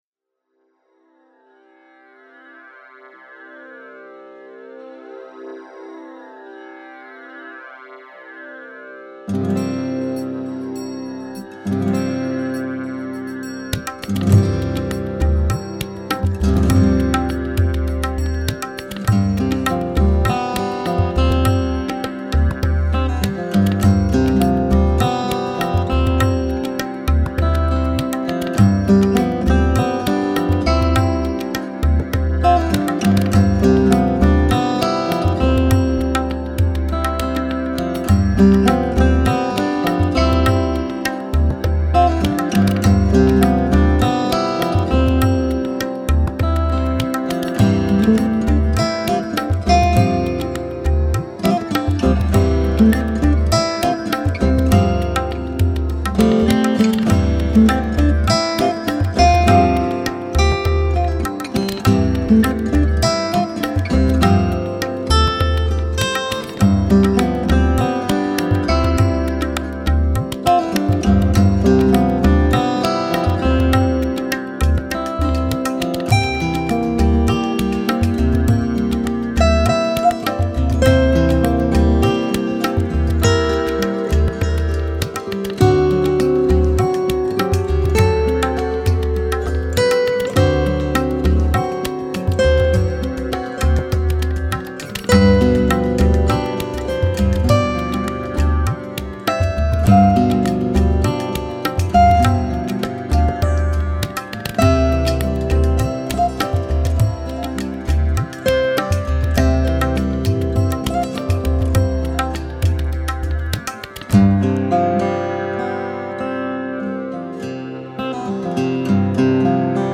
آرامش بخش , بومی و محلی , گیتار , ملل